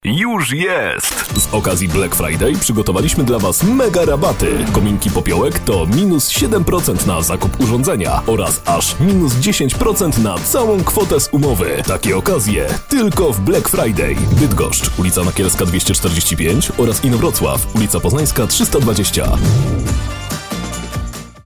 Male 20-30 lat
Radio and TV voice artist with a low voice timbre.
Spot reklamowy